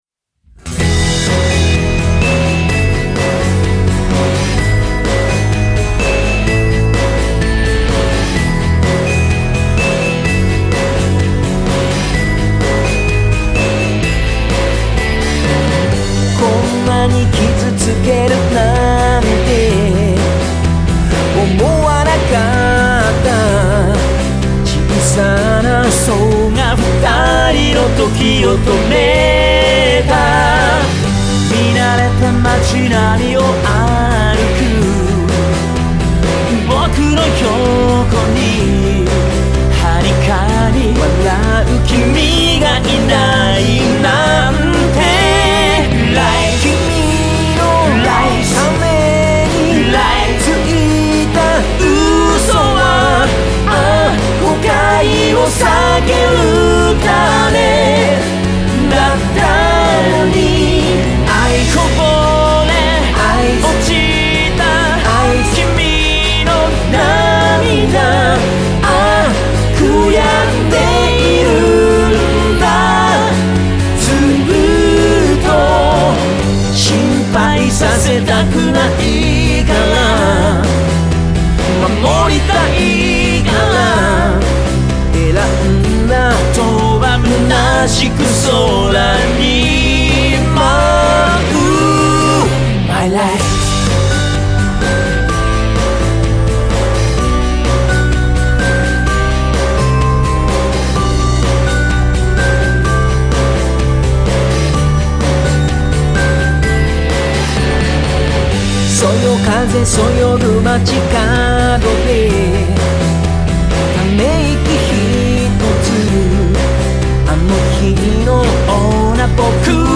バルジャン2人×ジャベールの三声ハーモニーヴァージョンはレアですよ。
7月のコンサートからの魅惑のライブ音源もオンエアしますからね！